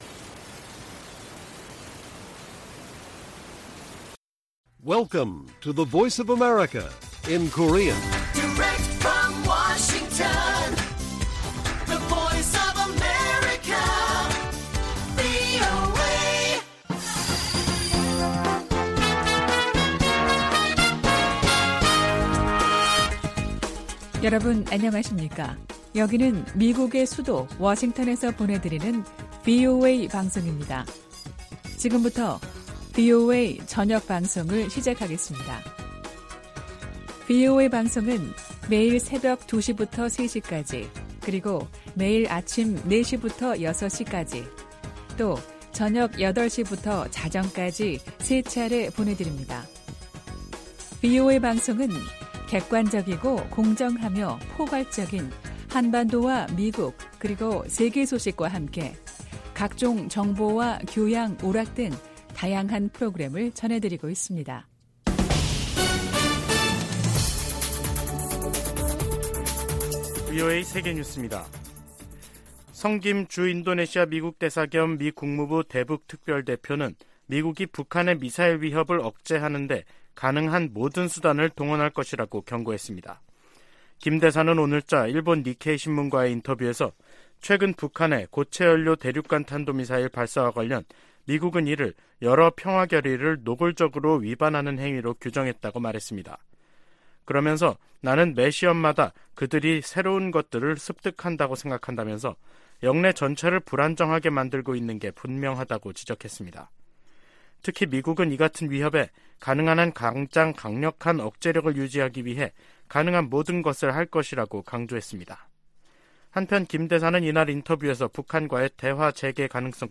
VOA 한국어 간판 뉴스 프로그램 '뉴스 투데이', 2023년 4월 19일 1부 방송입니다. 주한미군사령관은 북한 미사일이 워싱턴에 도달할 역량을 갖고 있으며, 7차 핵실험은 시간 문제라고 평가했습니다. 김정은 국무위원장이 첫 군사정찰위성 발사준비를 지시해 머지않아 위성발사 명분 도발에 나설 것으로 보입니다. 토니 블링컨 미 국무장관이 북한의 탄도미사일 발사와 핵 개발을 국제사회 공동 대응 과제로 꼽았습니다.